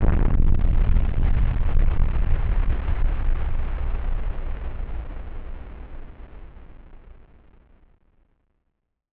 BF_DrumBombB-01.wav